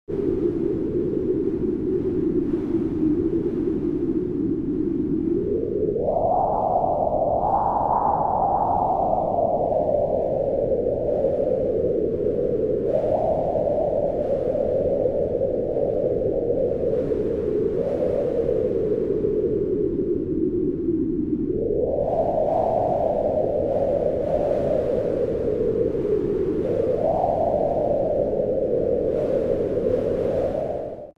دانلود صدای باد 29 از ساعد نیوز با لینک مستقیم و کیفیت بالا
جلوه های صوتی